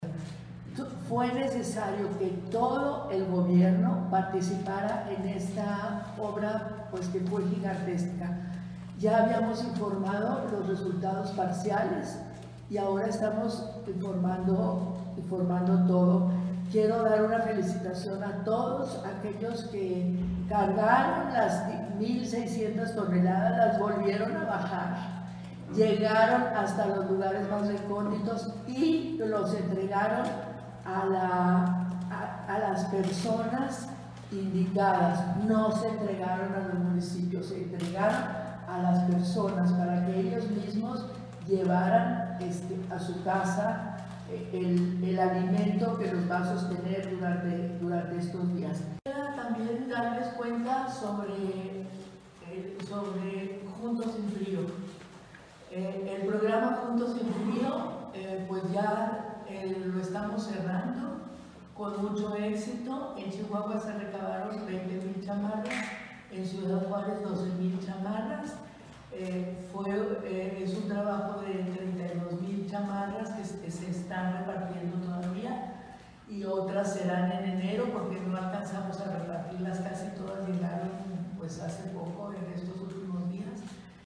AUDIO: MARÍA EUGENIA CAMPOS ANTILLÓN, PRESIDENTA DEL DEL DESARROLLO INTEGRAL DE LA FAMILIA (DIF ESTATAL)